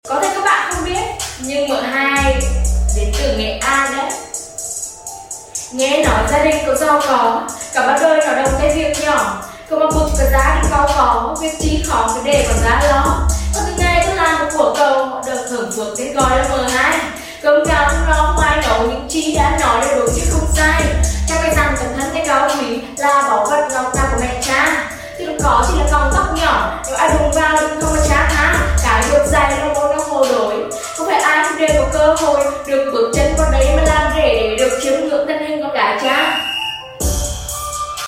Thu tiếng trực tiếp luôn sound effects free download